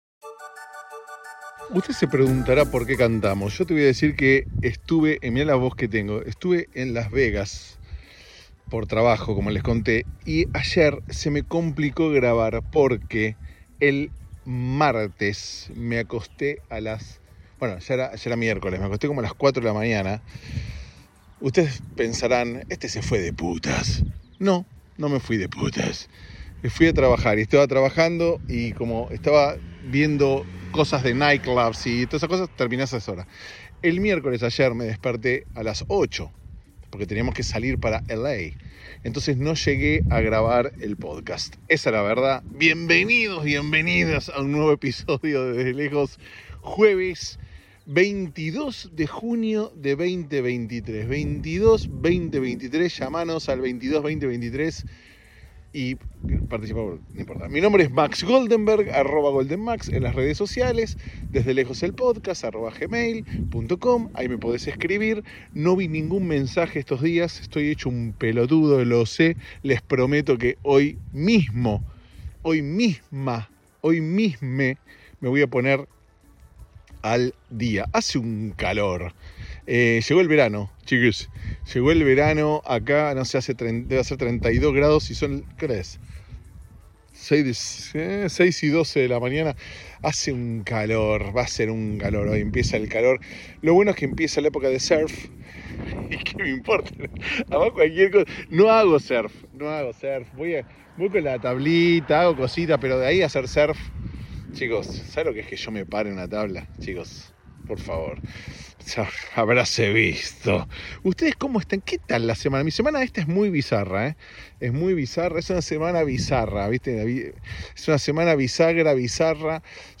Incluye una canción muy linda también.